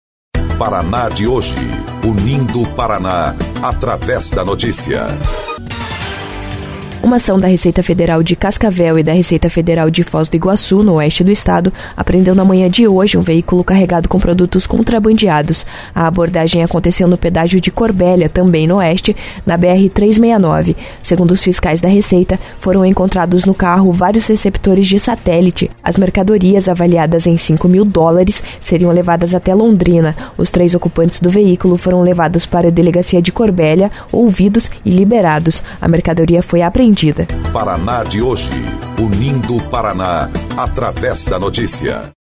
16.08 – BOLETIM – Ação da Receita Federal apreende cinco mil dólares em mercadoria contrabandeada